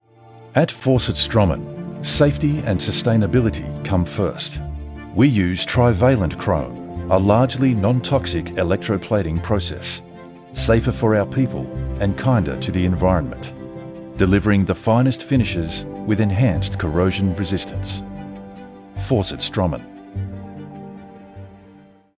Inglês (australiano)
Vídeos Explicativos
BarítonoGravesContraltoProfundoBaixo